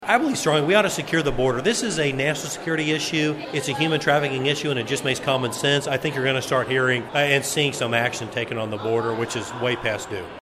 Congressman Tracey Mann met with constituents Tuesday at the Wefald Pavilion in City Park, part of his ongoing listening tour across the Big First district.